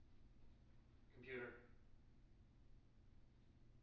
wake-word
tng-computer-282.wav